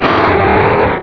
Cri de Latios dans Pokémon Rubis et Saphir.